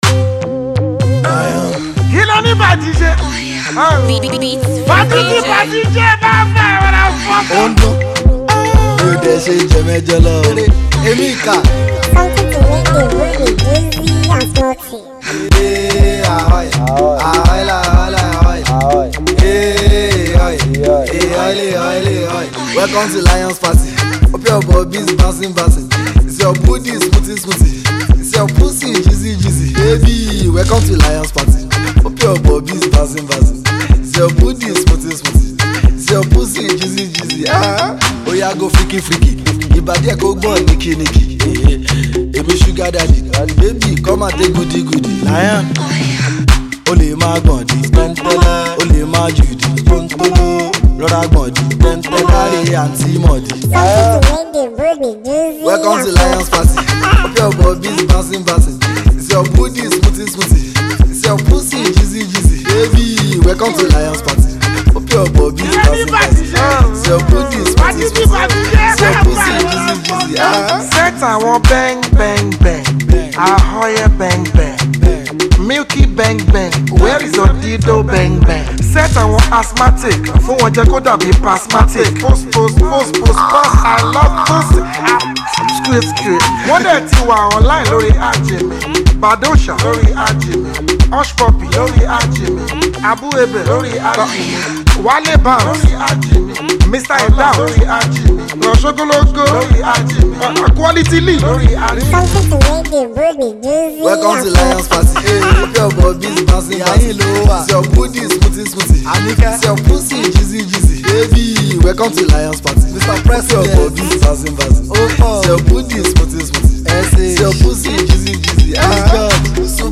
Street hop